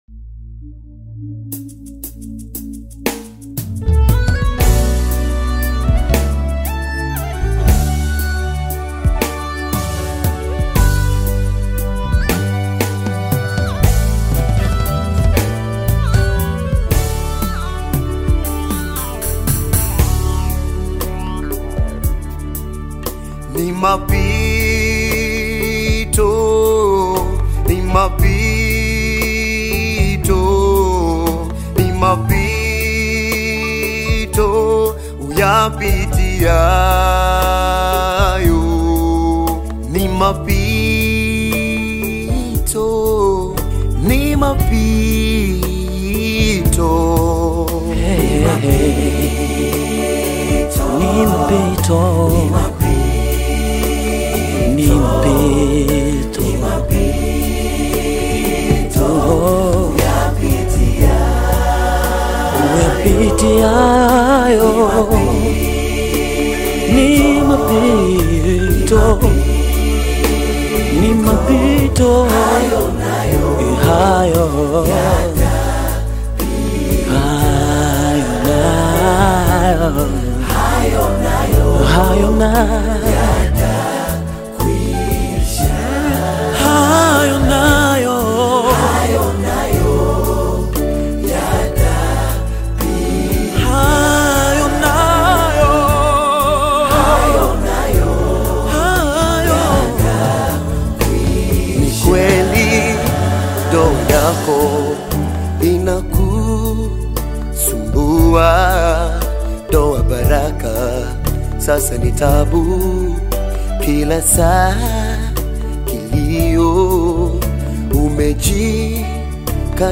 GOSPEL AUDIOS